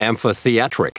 Prononciation du mot amphitheatric en anglais (fichier audio)
Prononciation du mot : amphitheatric